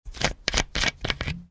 Звуки пластиковой крышки
Звук откручивания пластиковой крышки бутылки с водой